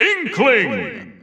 The announcer saying Inkling's name in English and Japanese releases of Super Smash Bros. Ultimate.
Inkling_English_Announcer_SSBU.wav